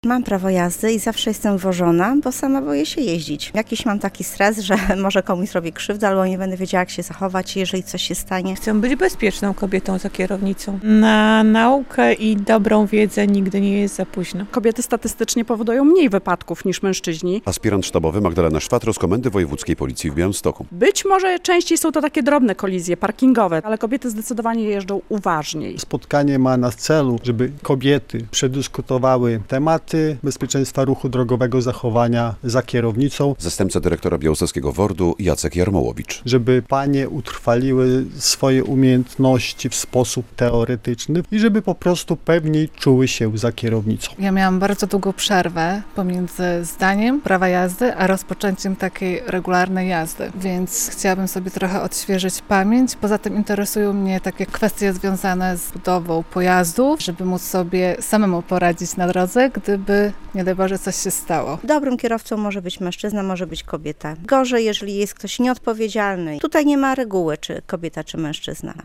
Szkolenie "Bezpieczna kobieta za kierownicą” z udziałem kilkudziesięciu pań - relacja
Panie mówią, że takie spotkanie jest dla nich bardzo cenne.